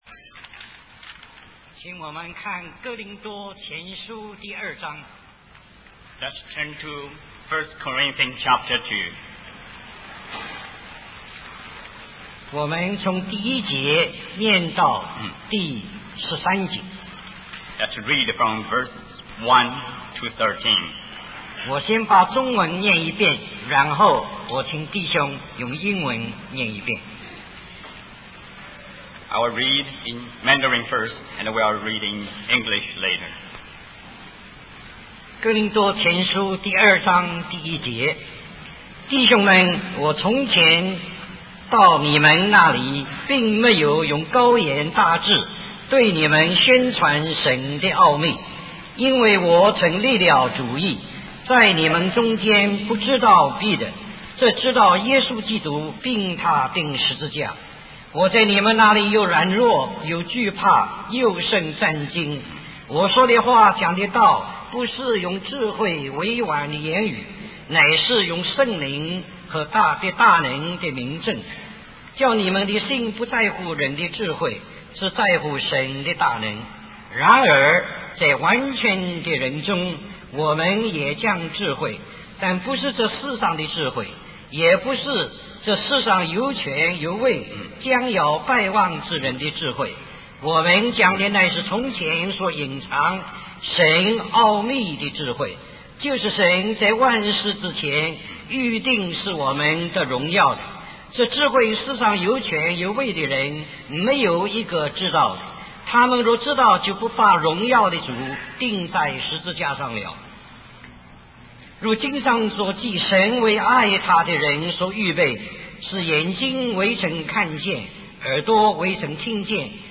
The theme of the conference is Christ and the Church, and the preacher urges the audience to pursue a deeper understanding of the church according to God's heart before the imminent return of Christ.